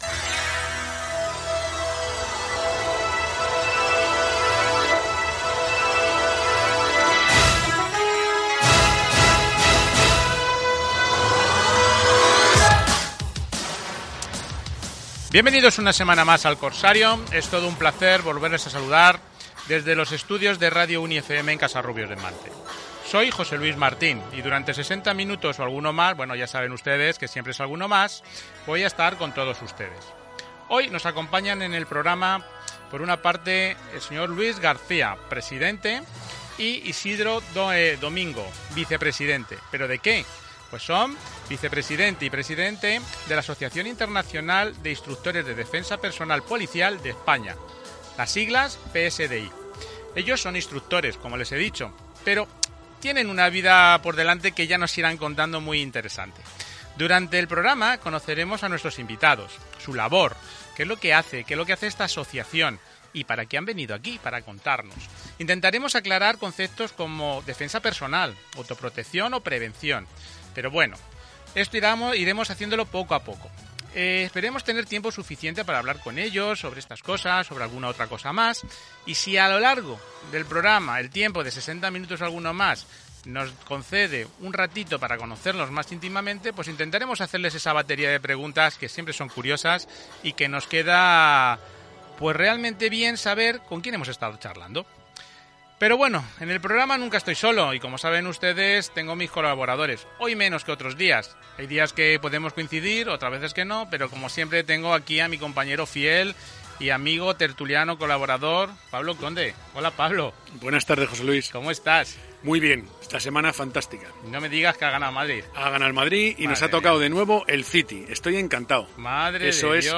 Entrevista radio psdi
entrevista psdi.mp3